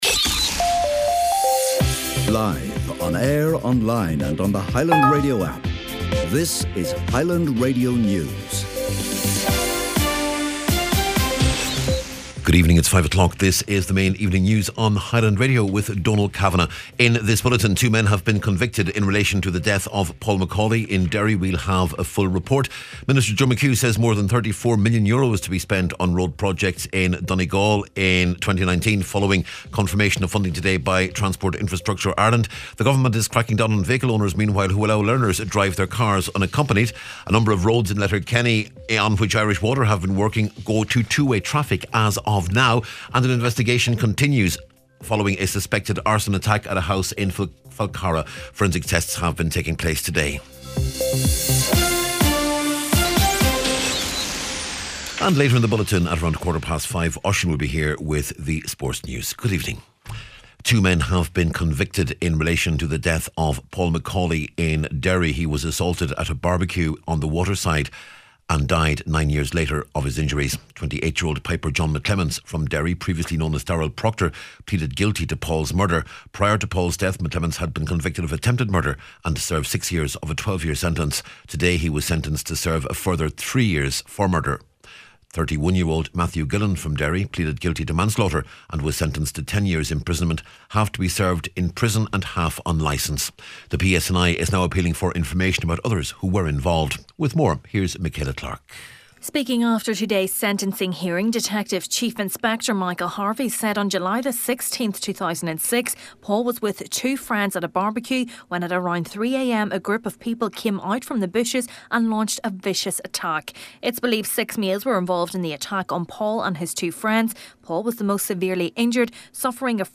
Main Evening News, Sport and Obituaries December 21st